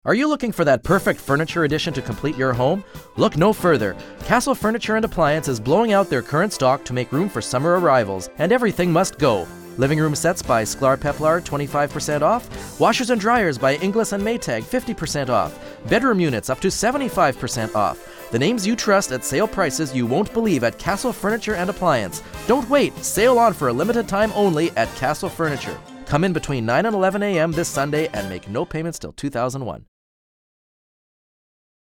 VOICEOVER ON CASTLE FURNITURE